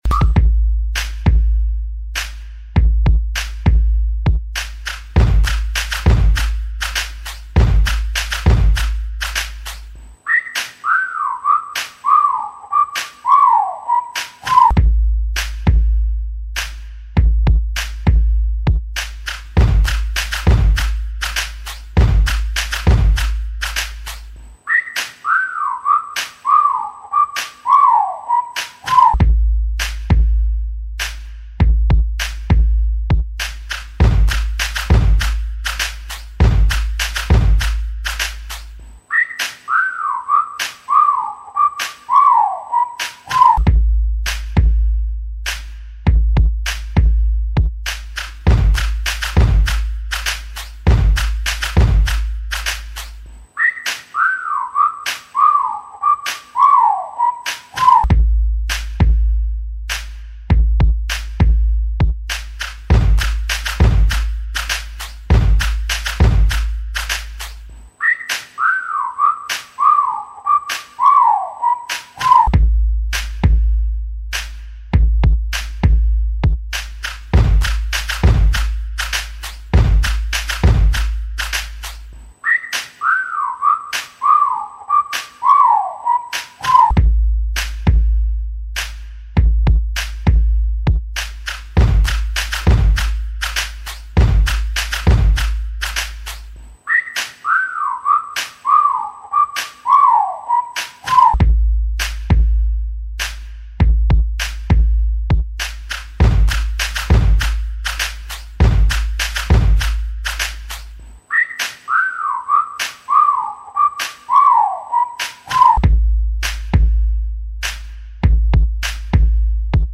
Категория: Instrumental